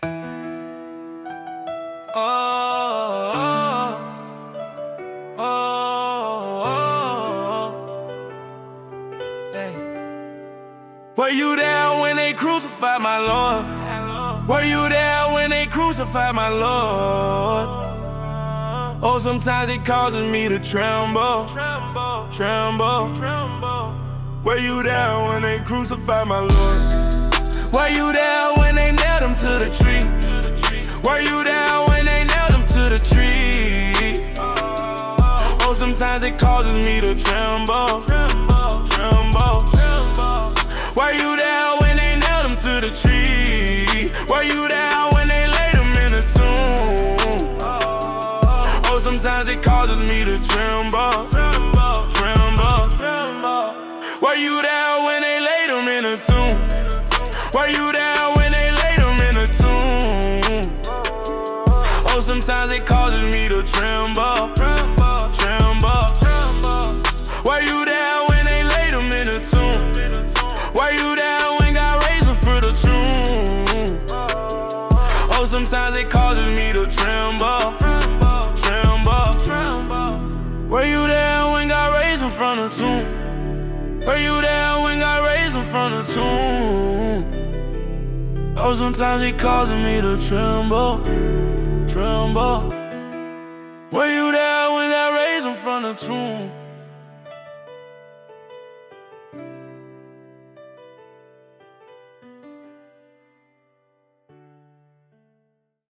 (Rap version of the traditional hymn)